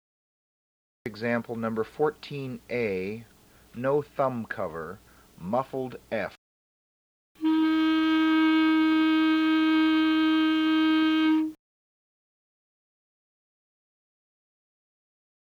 MUFFLED F or G
EXAMPLE #14a (Muffled F)
EXAMPLE #14b Muffled G)